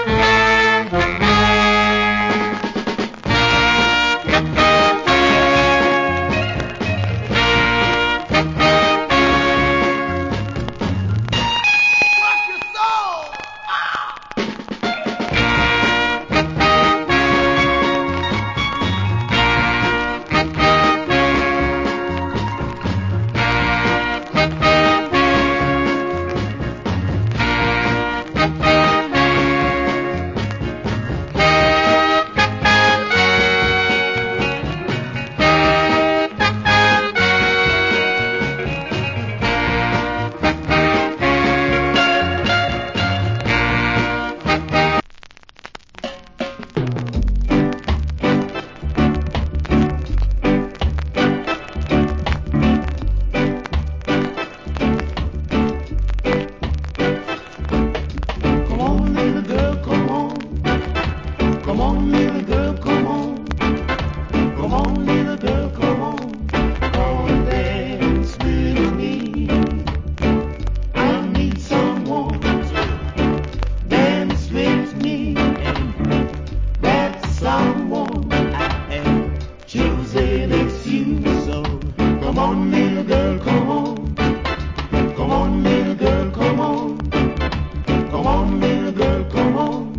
Nice Funky Inst.